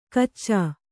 ♪ kaccā